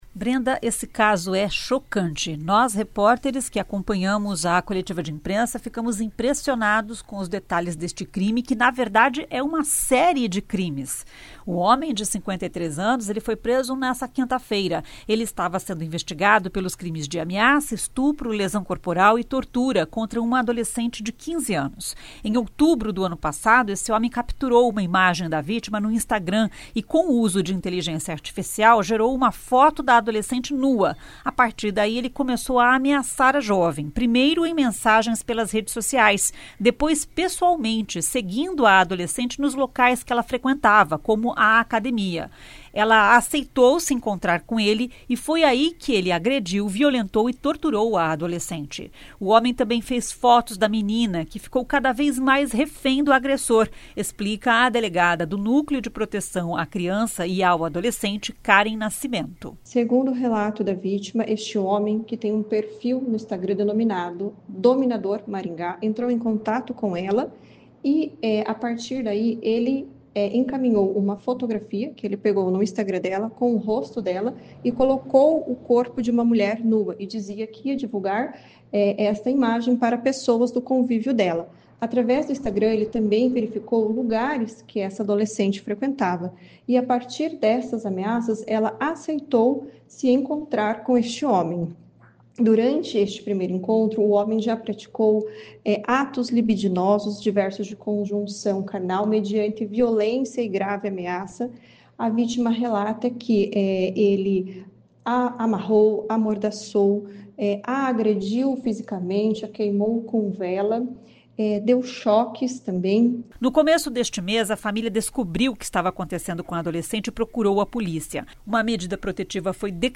Em coletiva de imprensa, a polícia deu mais detalhes sobre o crime.